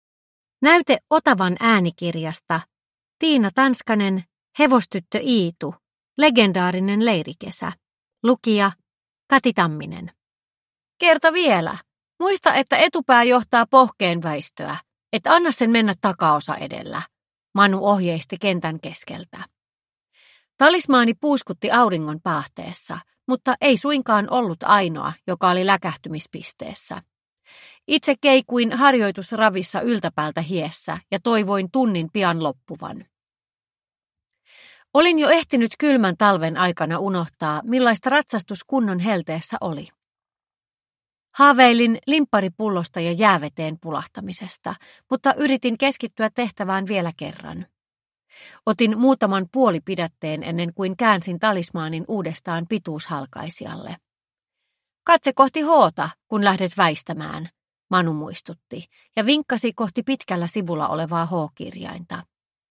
Legendaarinen leirikesä – Ljudbok – Laddas ner